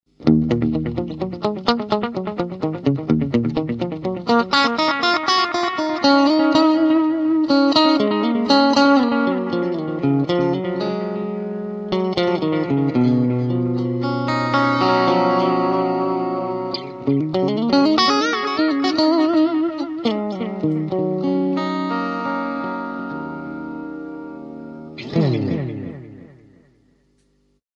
Analog Delay
Old Style Vintage Analog Echo Effect
Contrary to normal DIGITAL DELAY, this effect makes warm and friendly sound from ANALOG CIRCUIT.